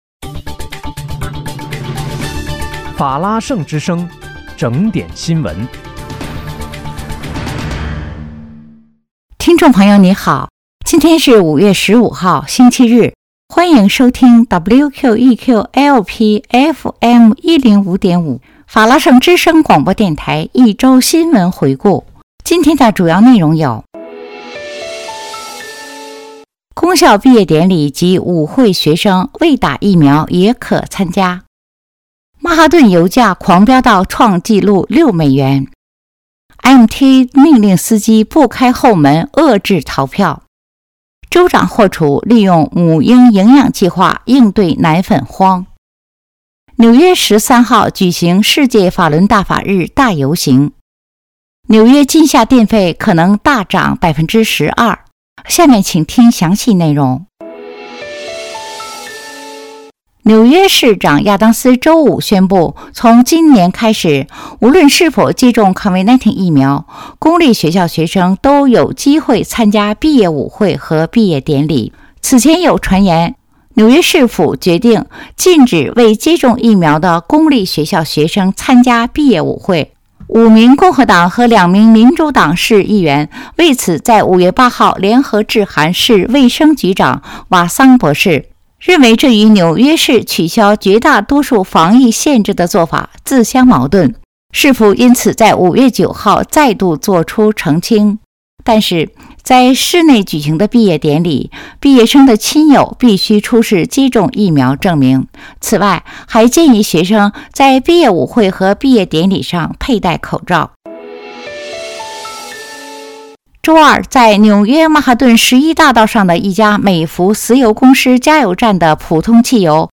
5月15日（星期日）一周新闻回顾